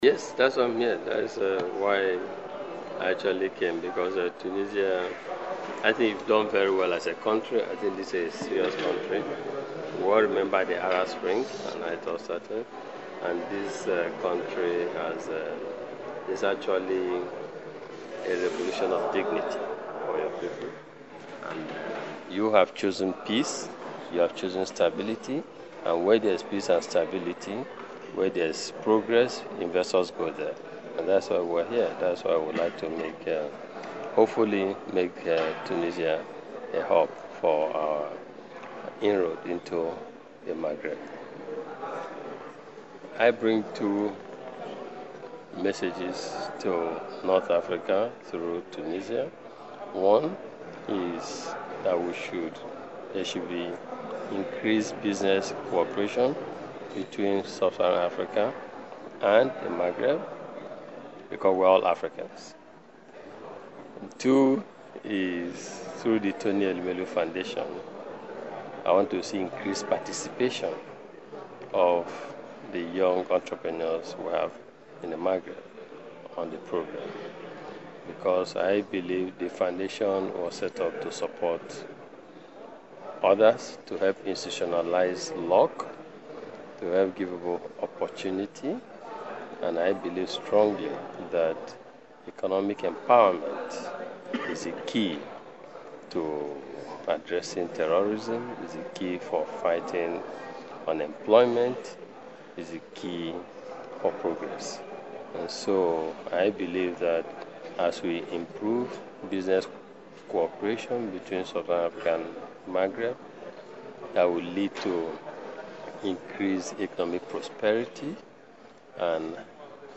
وقال طوني أيلومالو، في تصريح للجوهرة أف أم، إنه اختار تونس كوجهة ملائمة للاستثمار باعتبار مناخ الأعمال المشجع، والاستقرار الذي تعرفه البلاد بعد ثورة الكرامة، مشددا على ضرورة دفع التعاون الاقتصادي بين دول جنوب الصحراء ودول شمال افريقيا لما سيضمنه ذلك من تطوير للقارة في شتى المجالات.